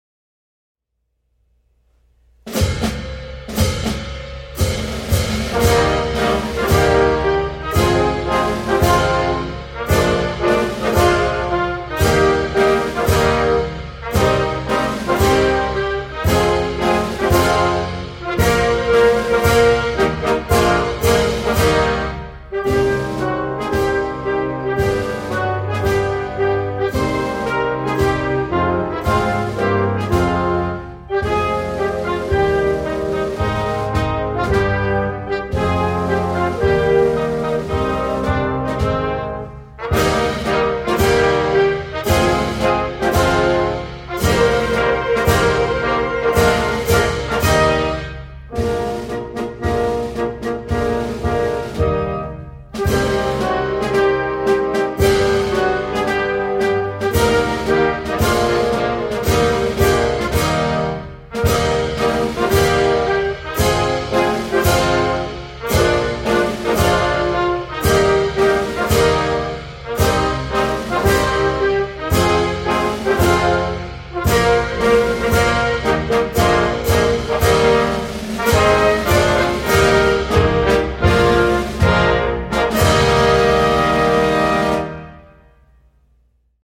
Mauritanian_anthem.mp3